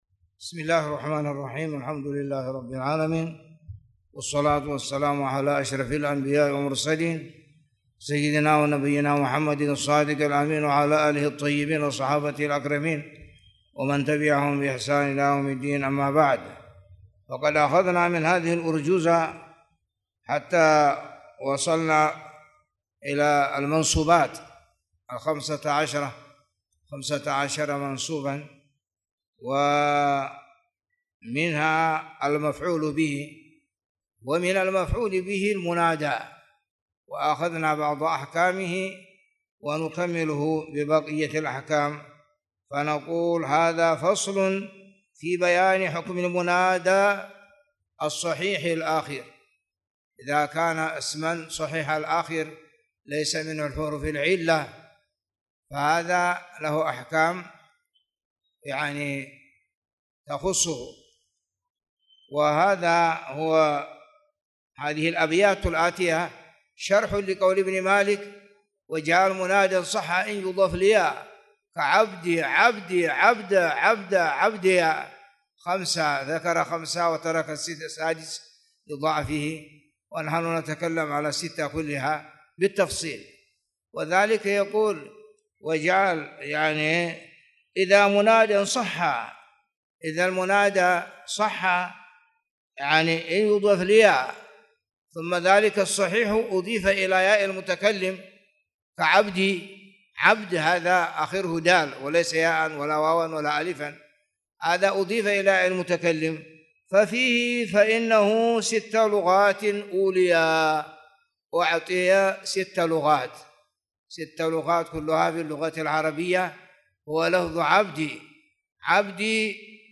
تاريخ النشر ٢٣ صفر ١٤٣٨ هـ المكان: المسجد الحرام الشيخ